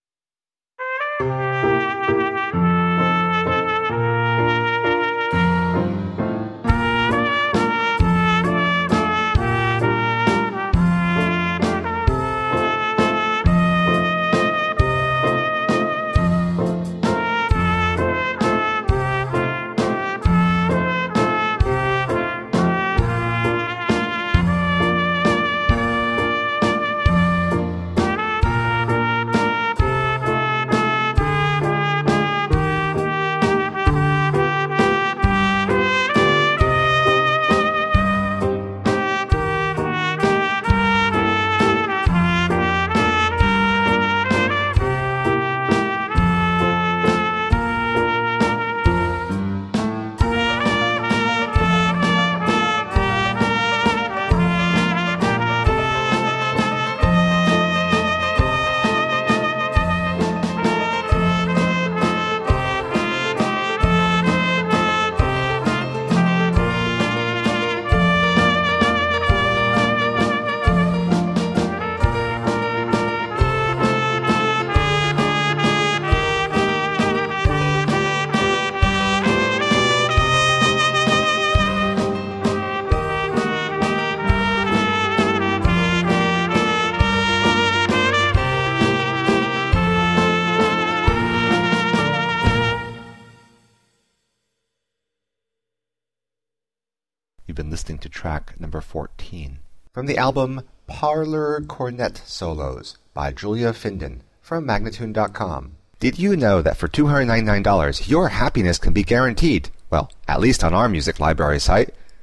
Delightful nostalgic melodies for cornet solo.